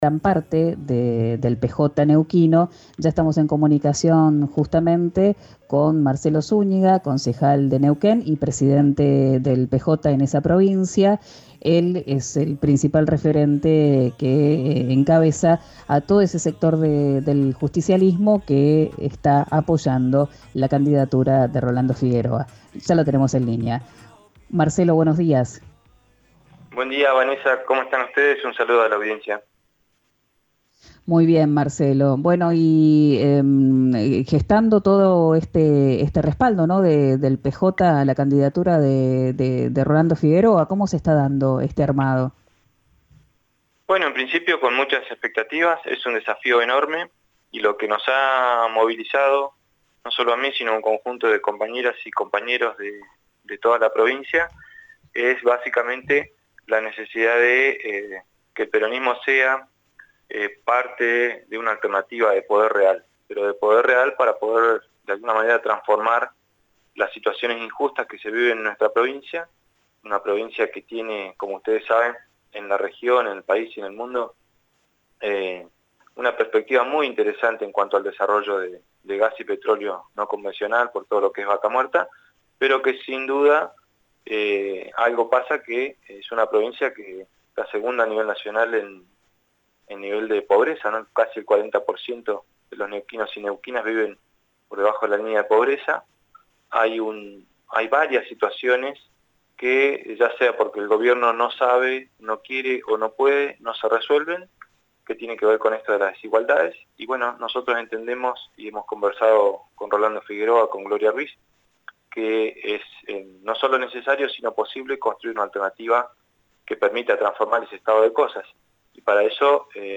Escuchá al concejal y presidente del PJ Neuquén, Marcelo Zúñiga, en «Quien dijo verano», por RÍO NEGRO RADIO.